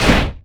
IMPACT_Generic_12_mono.wav